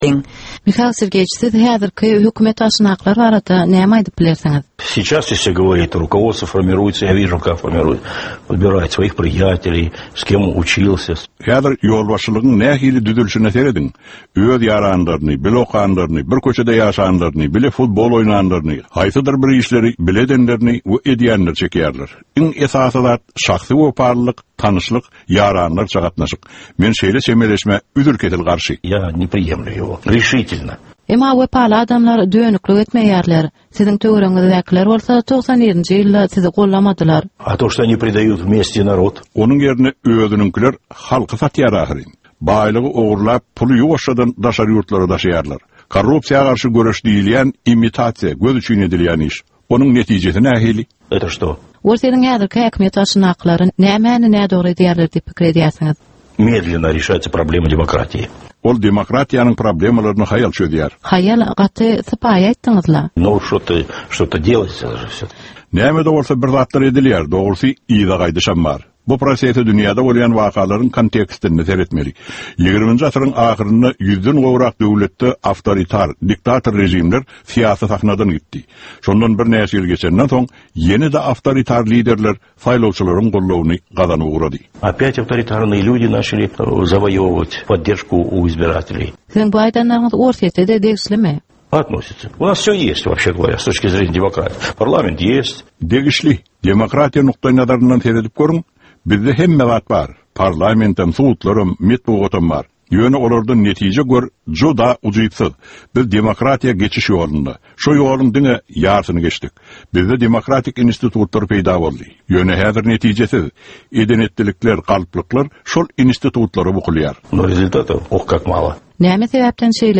Gorbaçýow bilen söhbet